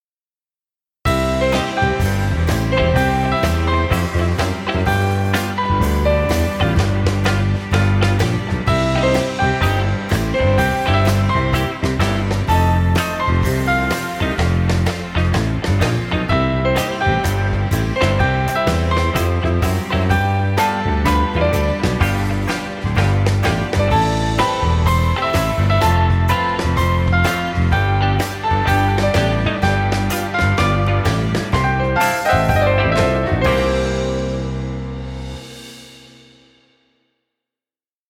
Folk music for video.